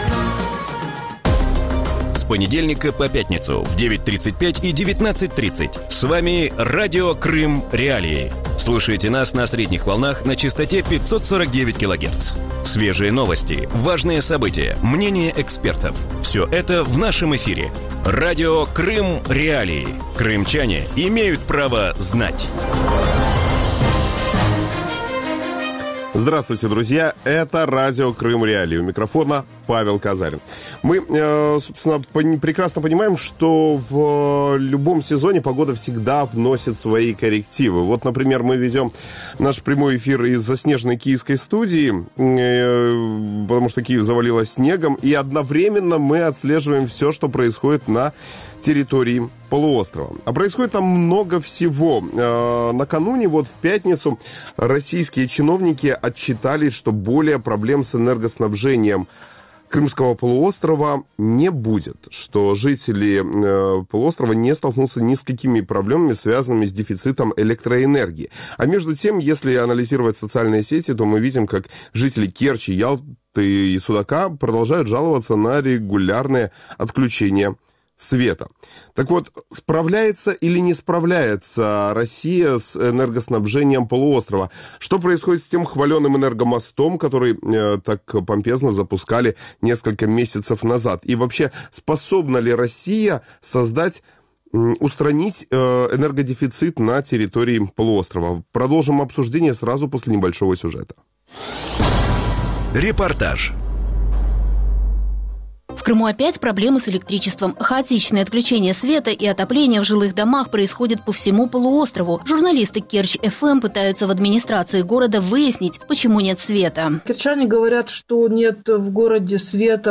В утреннем эфире Радио Крым.Реалии говорят о проблеме энергоснабжения полуострова. Жители Керчи, Ялты, Судака жалуются на регулярные отключения света.
Радио Крым.Реалии выходит по будням в 8:35 и 18:30 (9:35 и 19:30 в Крыму) на частоте 549 килогерц и на сайте Крым.Реалии. Крымчане могут бесплатно звонить в эфир